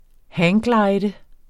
Udtale [ ˈhæːŋˌglɑjðə ]